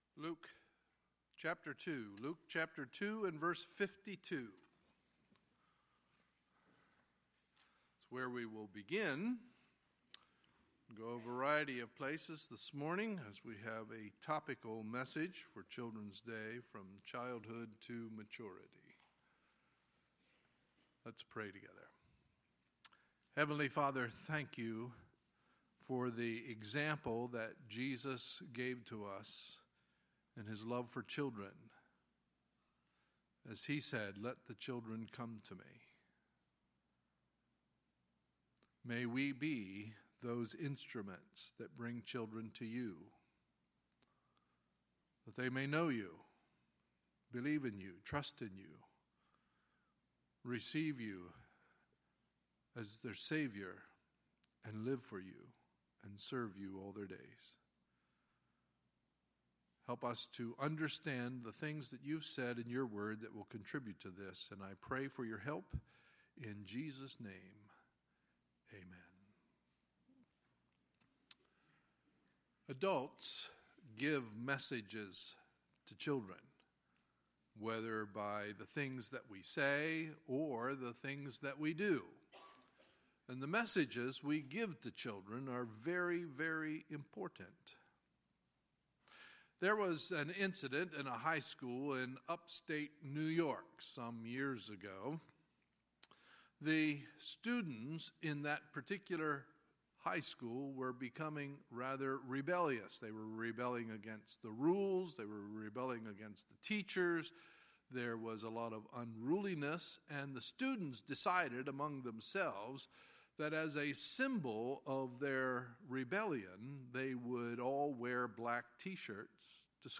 Sunday, May 21, 2017 – Sunday Morning Service